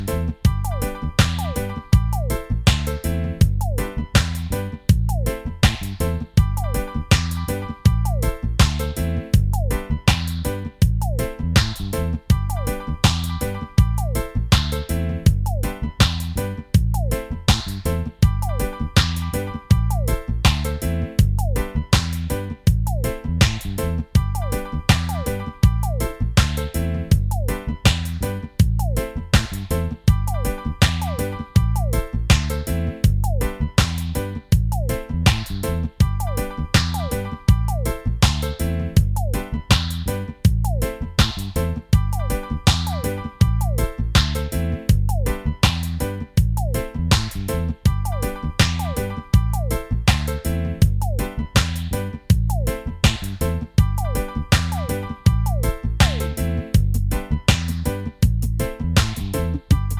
Minus Main Guitar For Guitarists 3:28 Buy £1.50